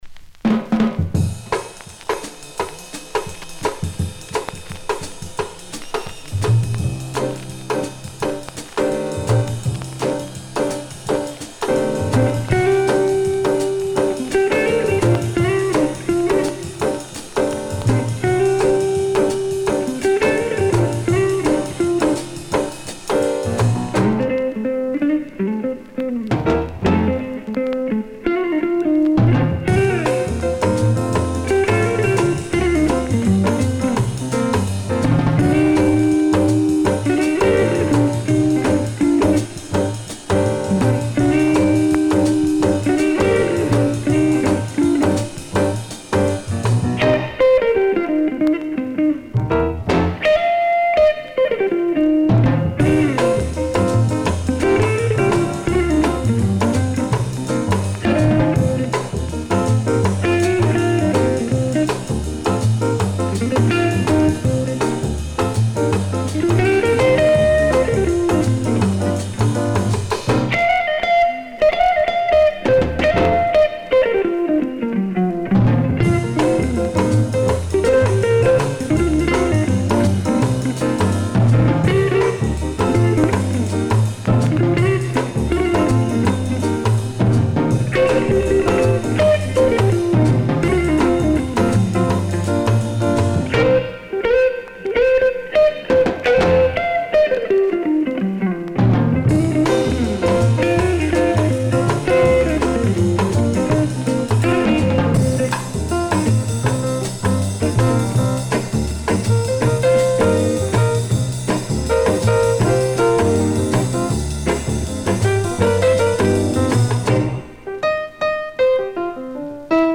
jazz guitar